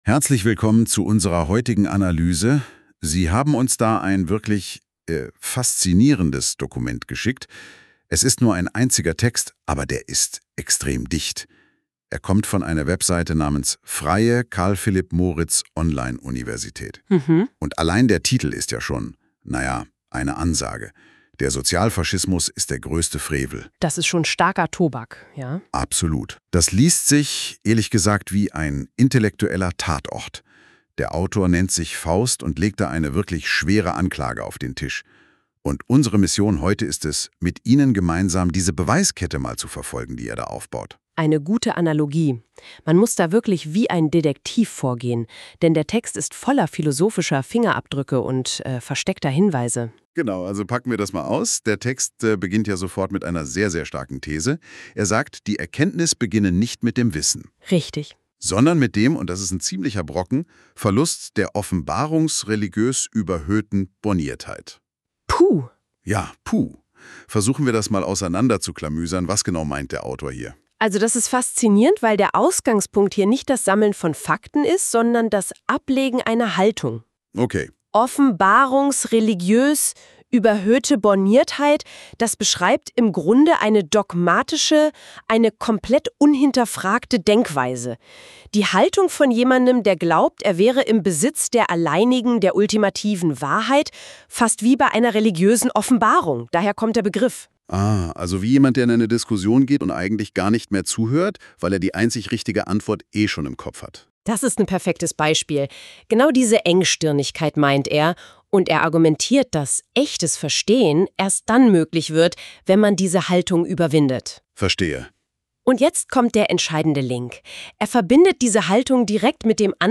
Podcast des poetologischen Studien-Textes im Stil einer Rezension